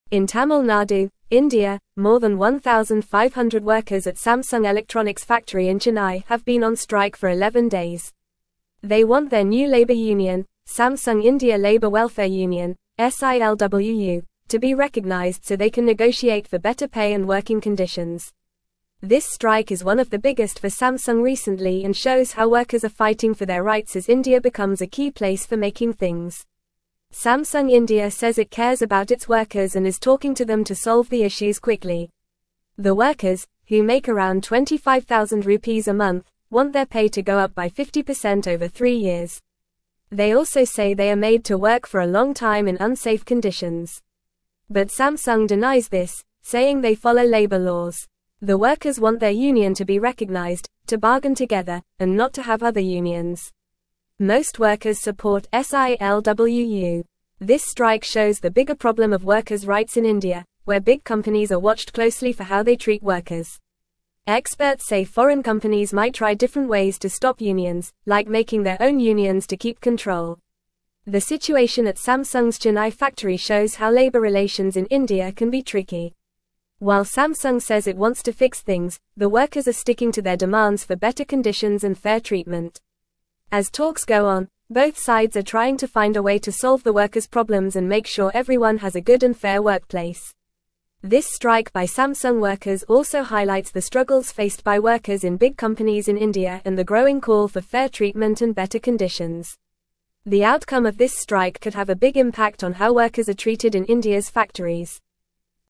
Fast
English-Newsroom-Upper-Intermediate-FAST-Reading-Samsung-Workers-in-India-Strike-for-Union-Recognition.mp3